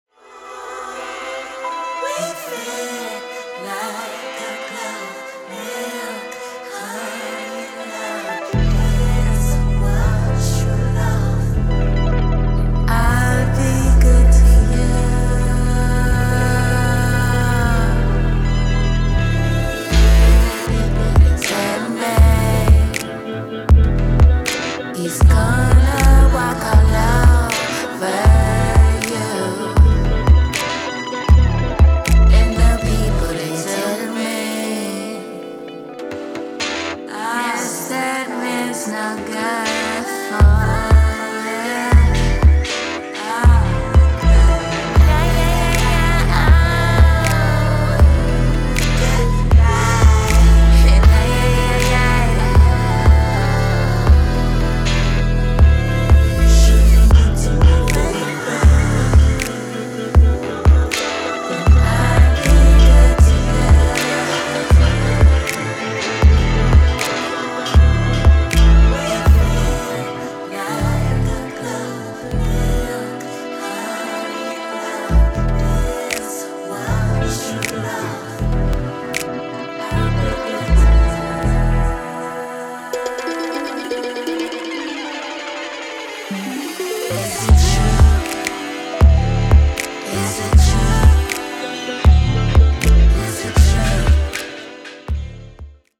where post-modern soul meets dub lullabies and much more.
head-nodding paradise